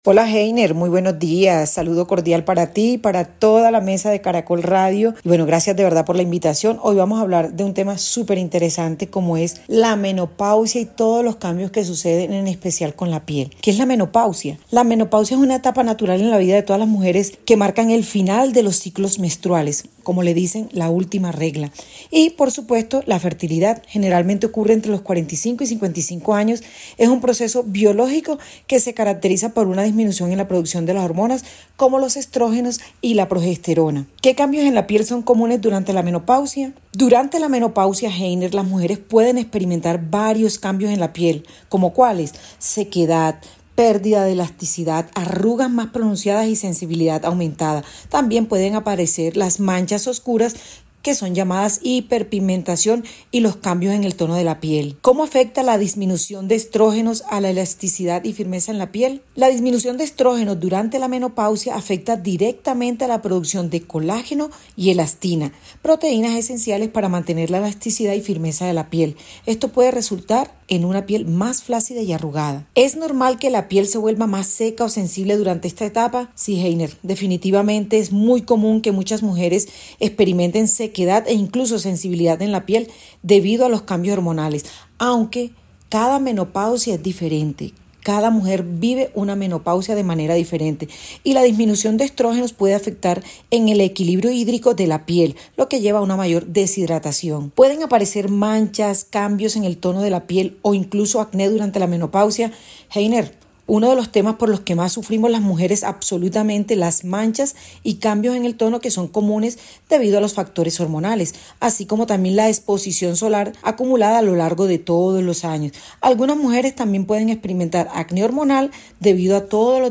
Escuche a continuación la entrevista completa con esta experta en cuidado facial de la región Caribe.